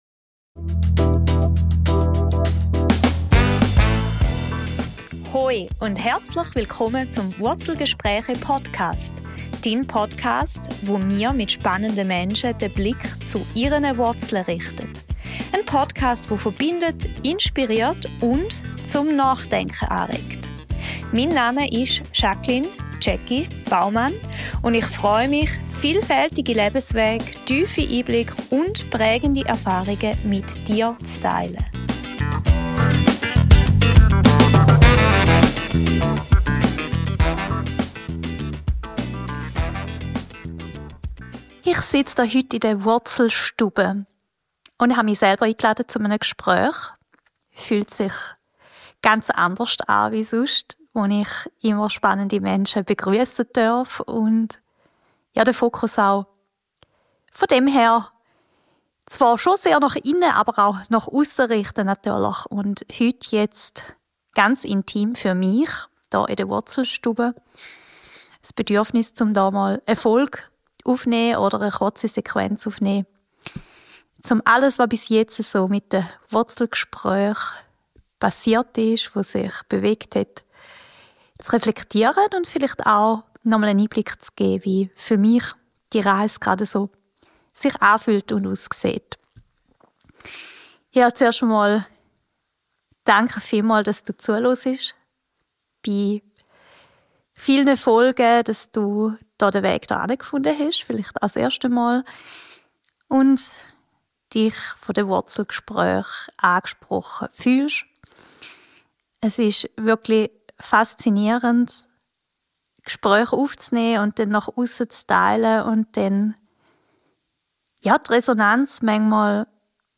Mut zur Tiefe – was das Podcasten in mir bewegt. Diese Bonusfolge der Wurzelgespräche ist ein leiser Blick nach innen.